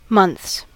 Ääntäminen
Ääntäminen RP : IPA : /mʌnθs/ US : IPA : /mʌnθs/ Haettu sana löytyi näillä lähdekielillä: englanti Käännöksiä ei löytynyt valitulle kohdekielelle.